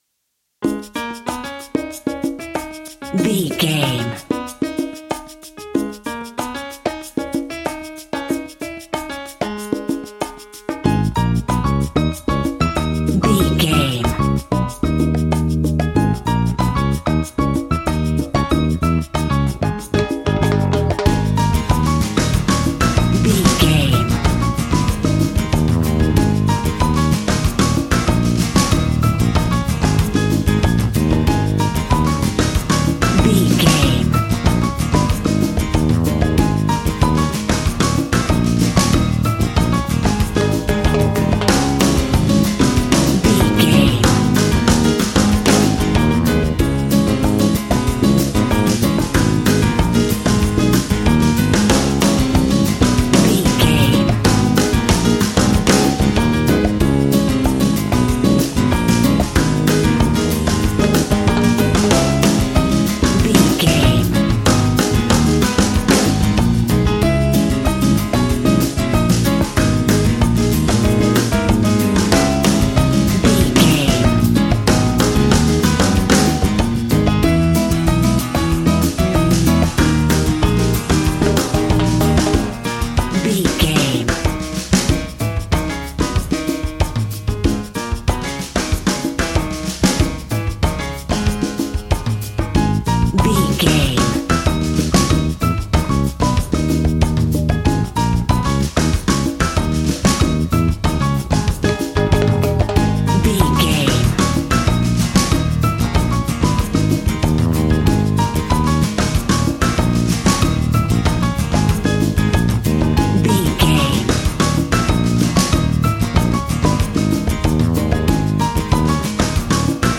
Aeolian/Minor
A♭
funky
energetic
romantic
percussion
electric guitar
acoustic guitar